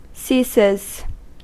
Ääntäminen
Ääntäminen US Haettu sana löytyi näillä lähdekielillä: englanti Käännöksiä ei löytynyt valitulle kohdekielelle. Ceases on sanan cease yksikön kolmannen persoonan indikatiivin preesens.